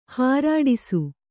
♪ hārāḍisu